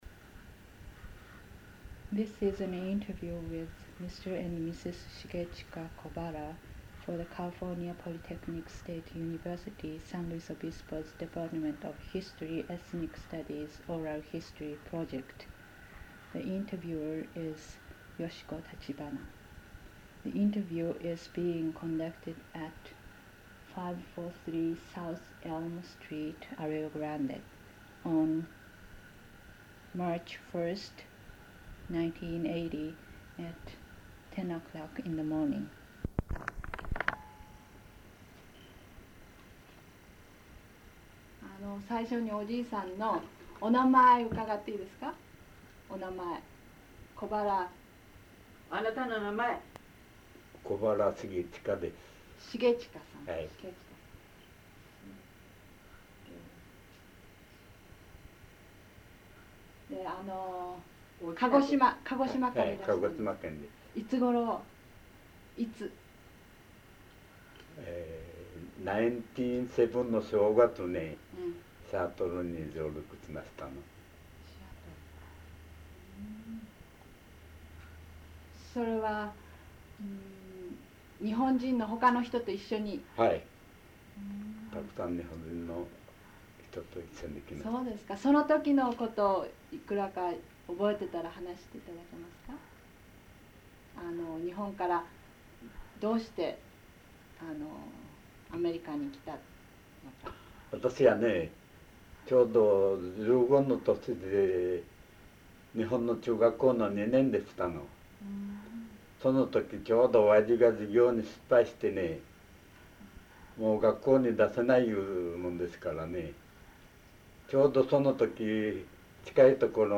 Form of original Transcript Audiocassette